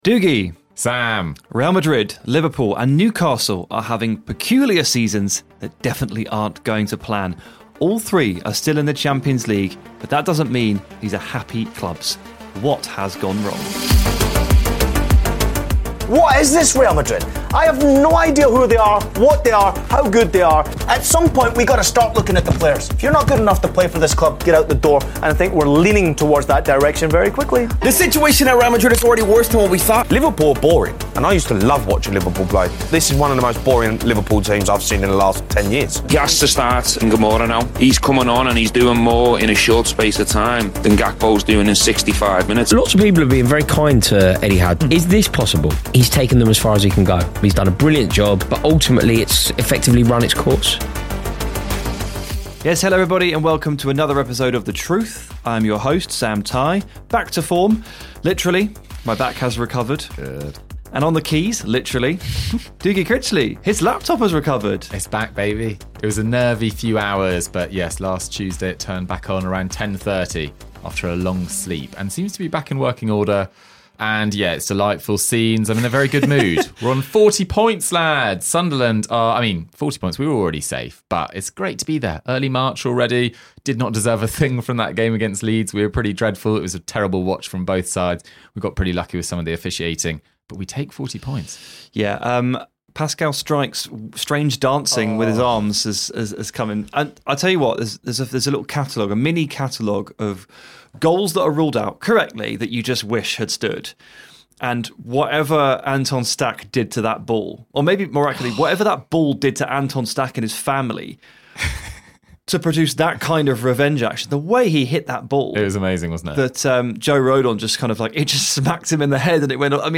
Ranks FC - A Football Podcast · E429 The Truth: Teams with Seasons on the Brink Play episode March 6 47 mins Bookmarks Episode Description It's time for The Truth!